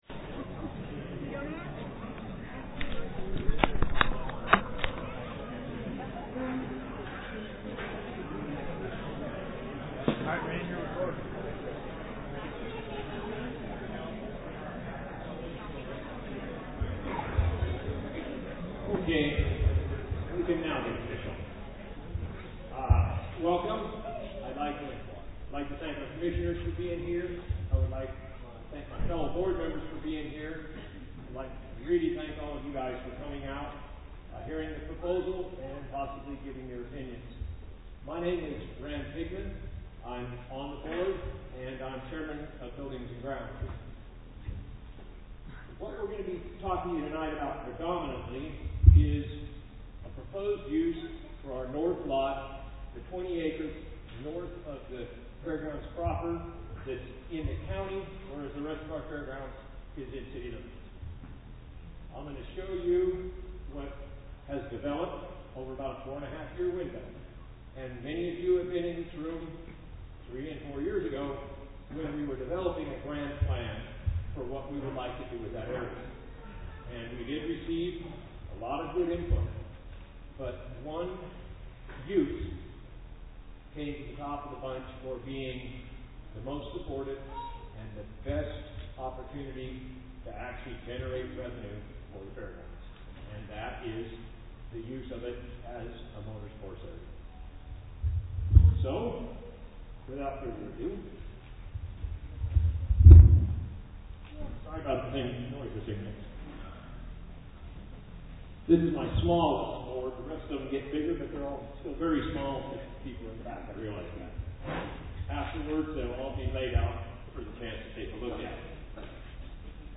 Audio Recording from Motorsports Meeting at Fairgrounds October 8th 2015